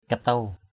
/ka-tau/ (d.) con chí = pou. louse. katuw akaok kt~| a_k<K chí = pou de tête. head louse. katuw aw kt~| a| rận = pou d’habit. louse of habit. katuw...